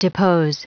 Prononciation du mot depose en anglais (fichier audio)
Prononciation du mot : depose